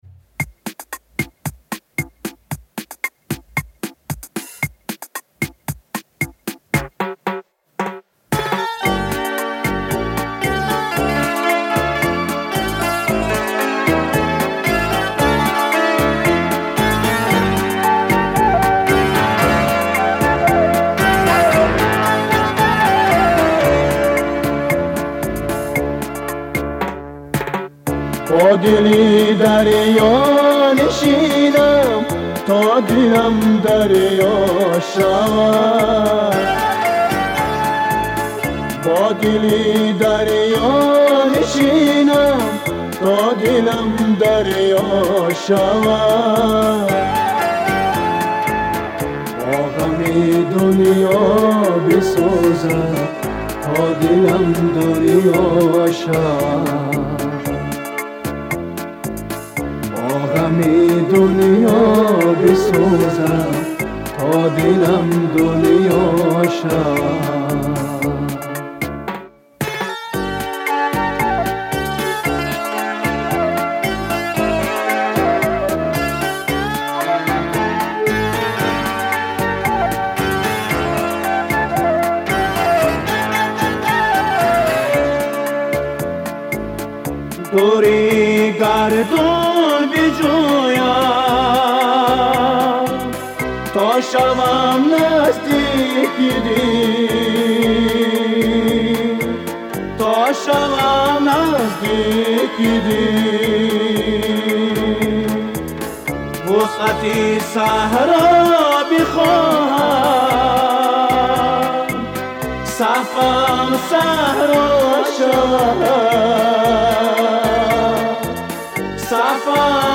Мусиқа ва тарона